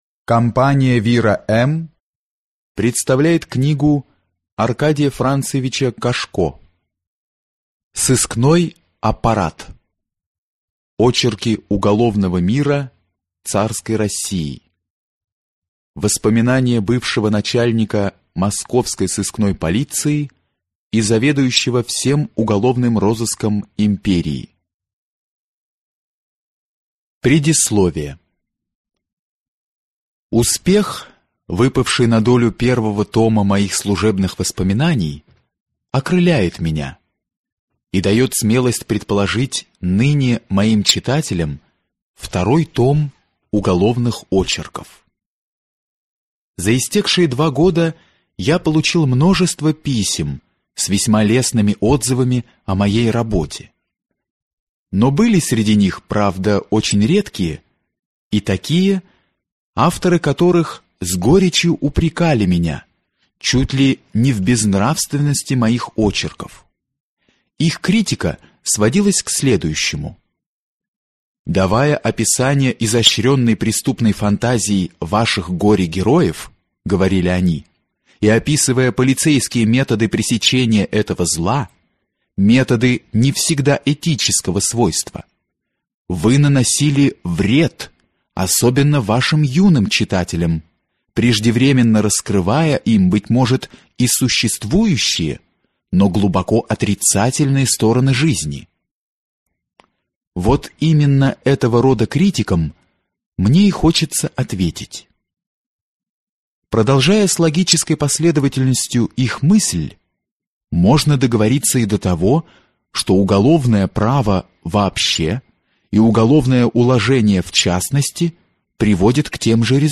Аудиокнига Сыскной аппарат | Библиотека аудиокниг